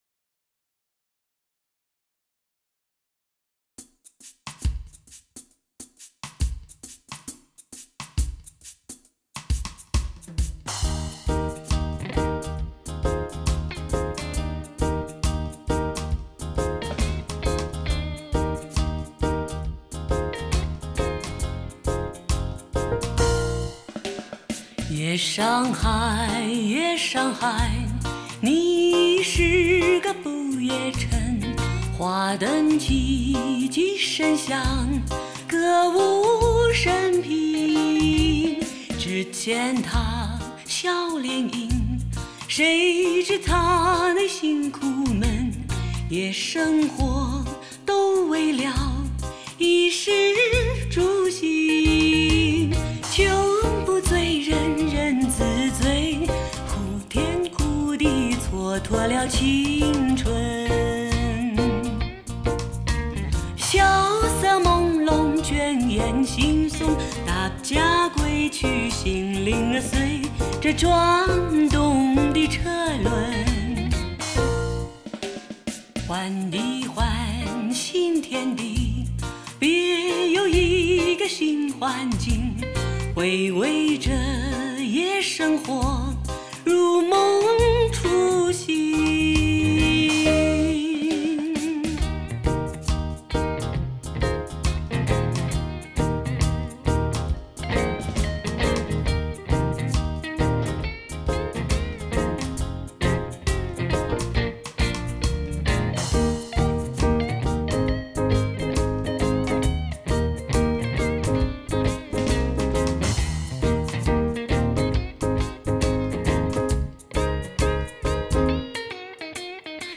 传奇真空管录音，dts ES6.1CD典藏，发烧极品、百听不厌；
典的旧时代歌曲，经过重新编曲，让老歌重新注入新的生命，保留了原的音乐的韵味
同时又突出了新时代性感磁性女声；
磁质的优美人声极富怀旧感，迷人歌声风韵令人骨头酥软，以最性感的磁性嗓音表现得淋漓尽致。